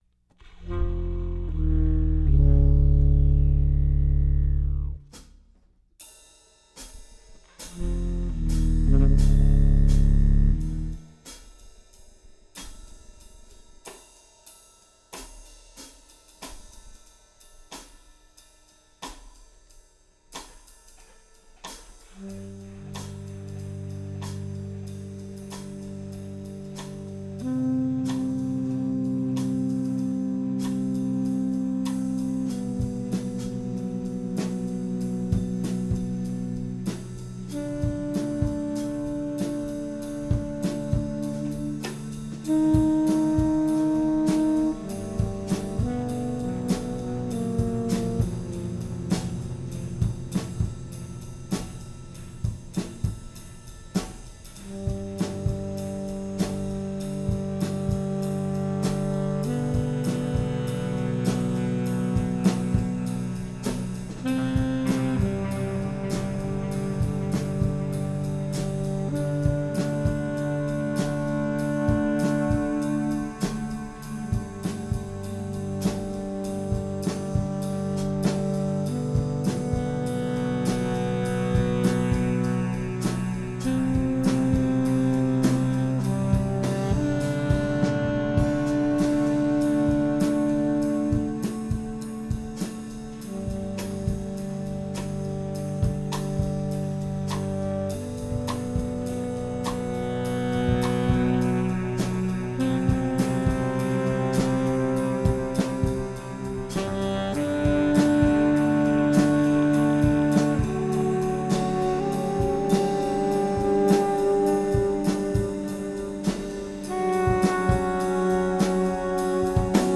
Recorded at the Maid’s Room,Â NYC September 22, 2013
drums
alto saxophone, fx
Stereo (Pro Tools)